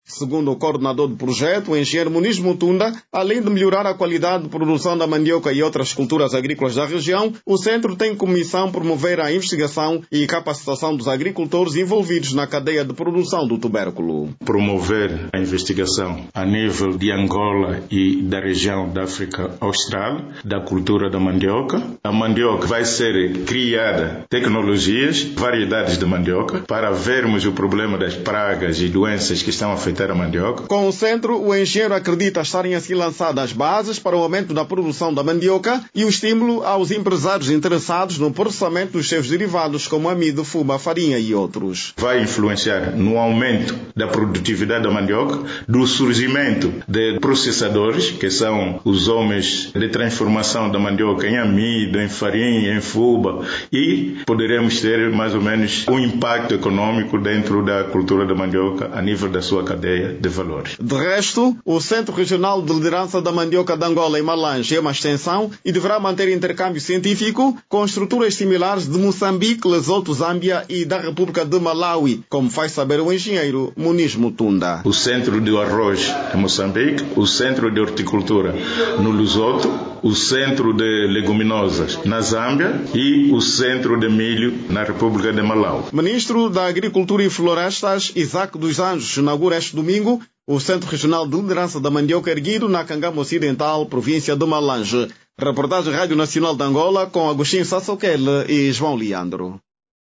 Clique no áudio abaixo e saiba mais com o jornalista  Clique no áudio abaixo e saiba mais com o jornalista